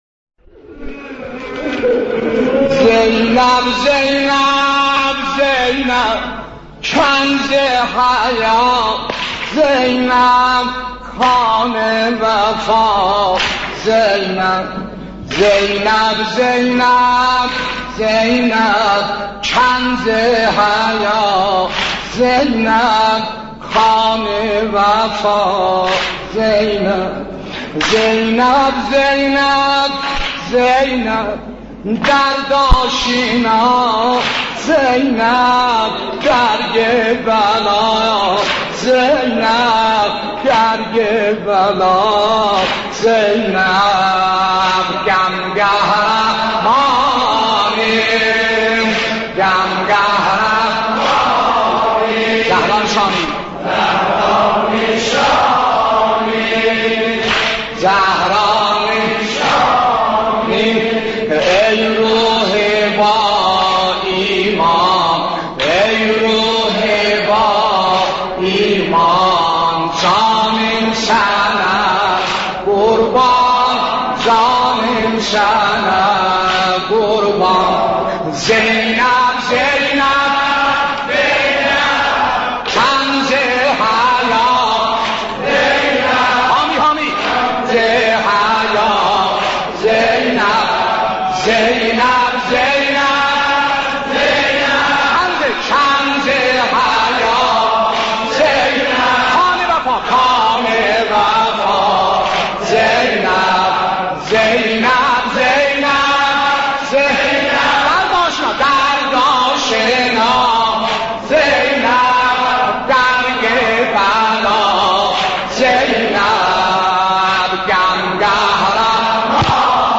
حاج سلیم موذن زاده
نوحه زینب زینب